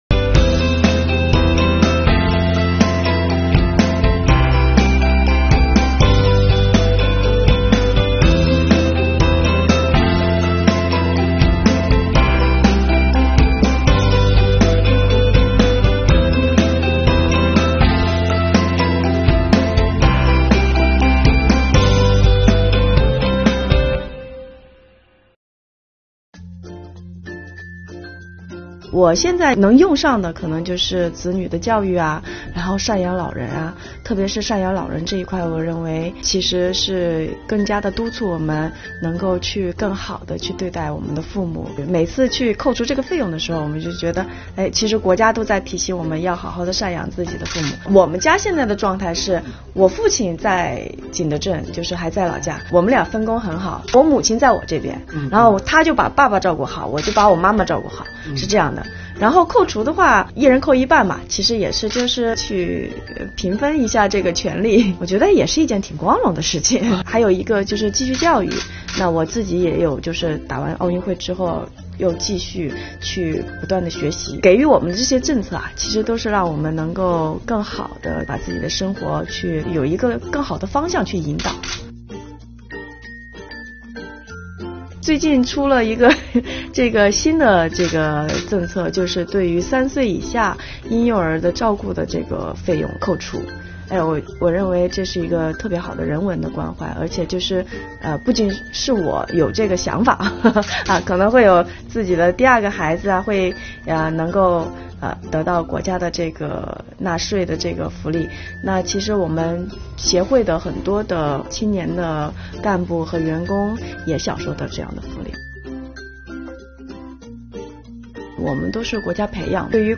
近日，本报记者对吴静钰进行了采访，听她讲述享受个人所得税专项附加扣除背后的故事。